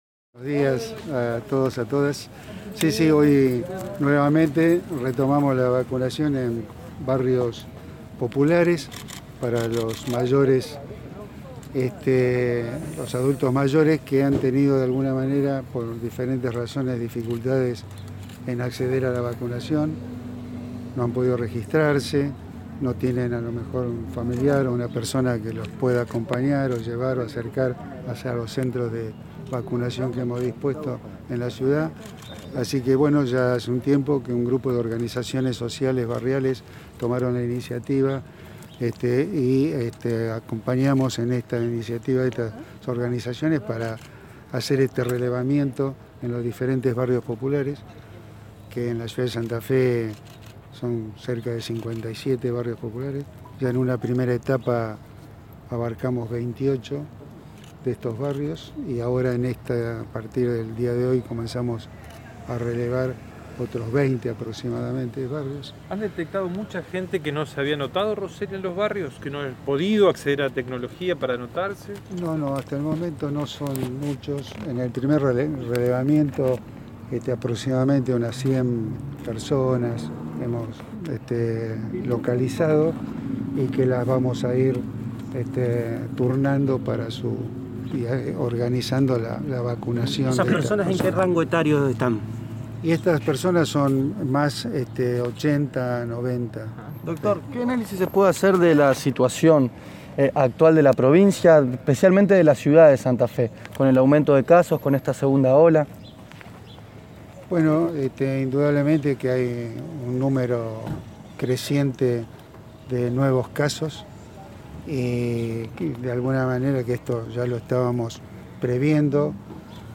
Por su parte, el director de la región Santa Fe, Rodolfo Roselli, indicó en dialogo con el móvil de Radio EME que «hubo cerca de 100 personas detectadas no vacunadas».
Escuchá la palabra de Rodolfo Roselli: